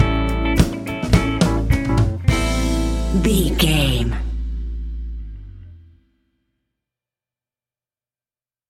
Ionian/Major
A♭
house
electro dance
synths
techno
trance
instrumentals